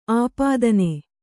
♪ āpādane